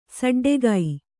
♪ saḍḍegai